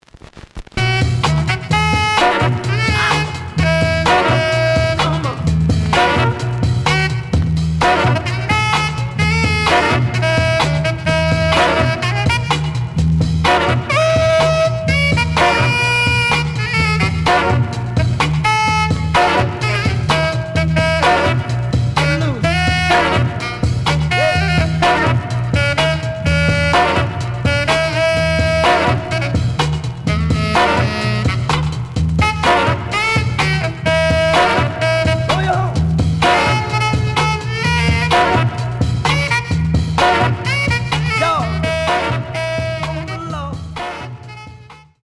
The audio sample is recorded from the actual item.
●Genre: Funk, 60's Funk
The recording is unstable at the start of the B side.